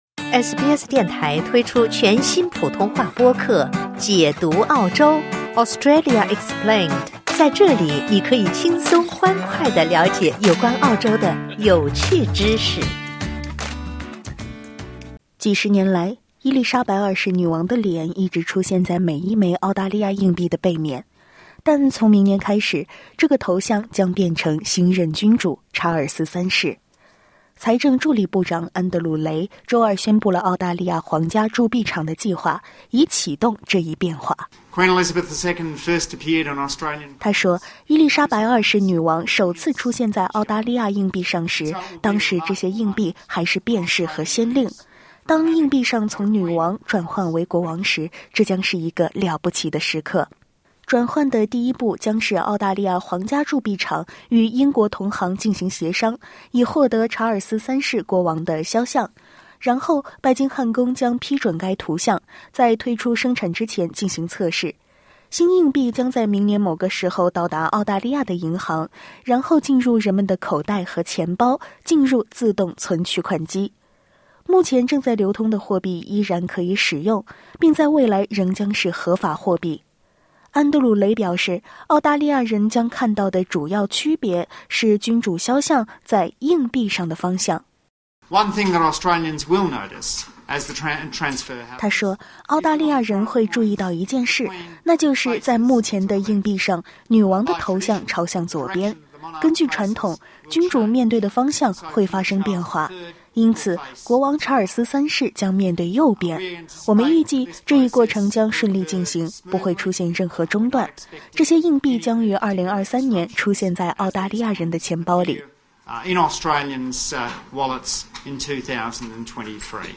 但从明年开始，新硬币上将开始出现查尔斯三世的头像。（点击上方音频收听报道）